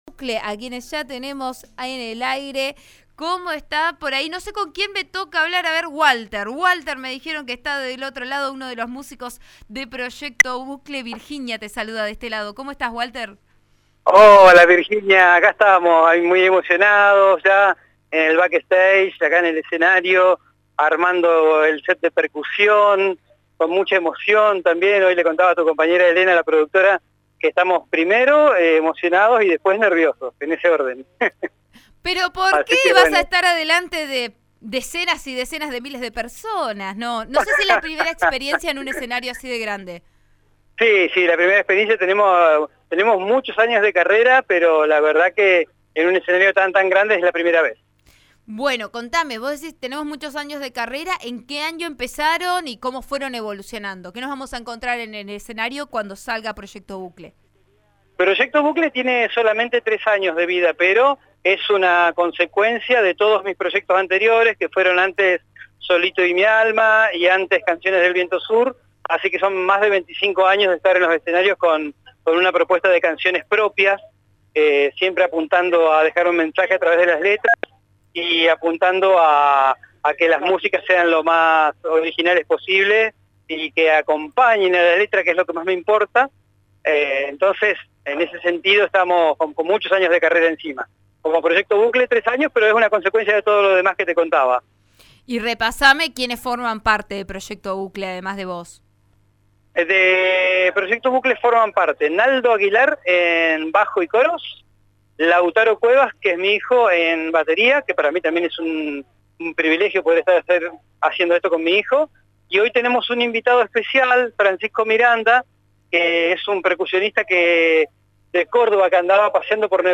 “Funciona todo bien a contrarreloj. Hay 20 minutos para tocar. No se puede pasar de eso”, contó el artista en RN Radio.